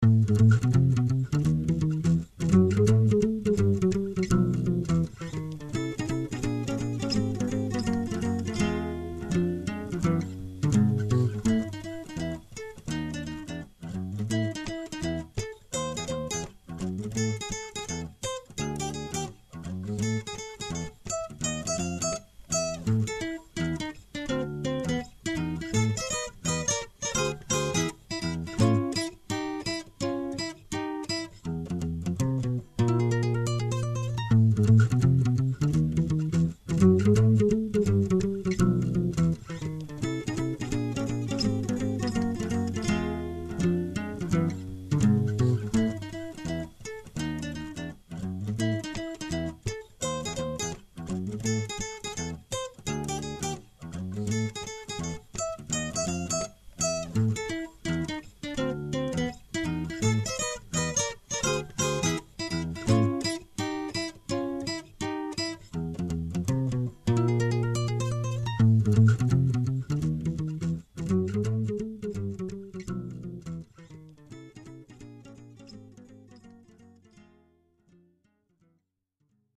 Guitar arrangements